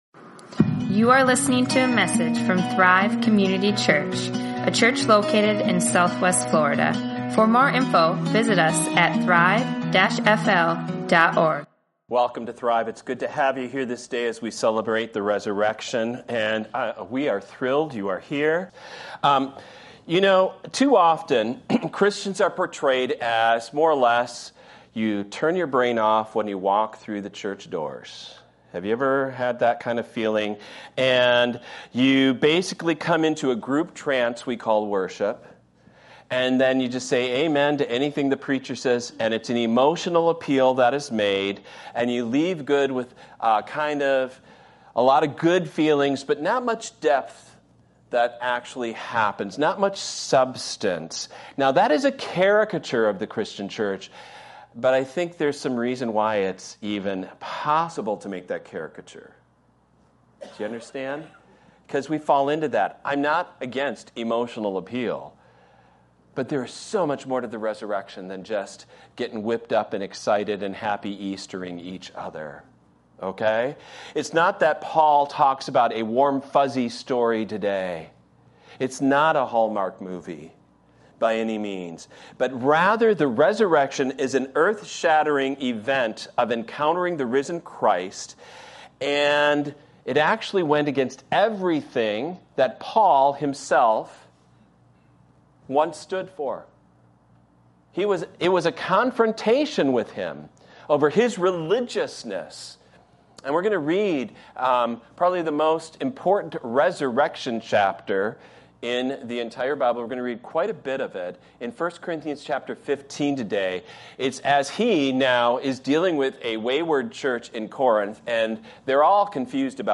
Easter 2023 | Sermons | Thrive Community Church